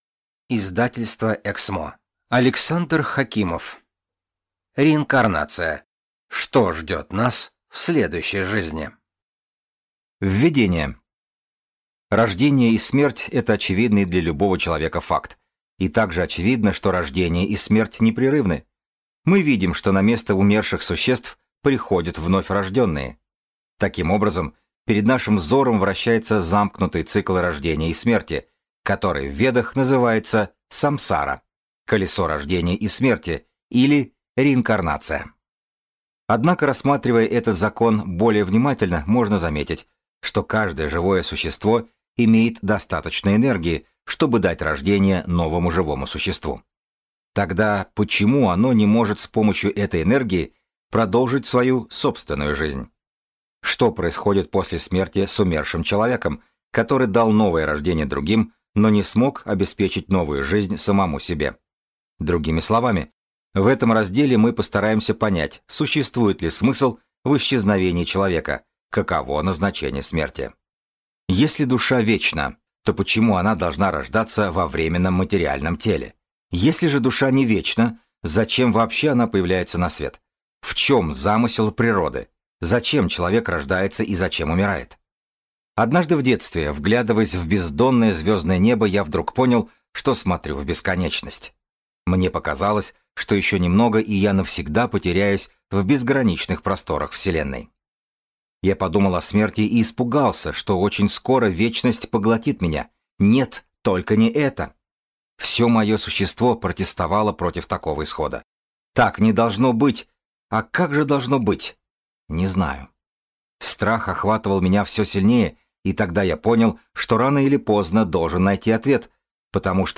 Аудиокнига Реинкарнация. Что ждет нас в следующей жизни?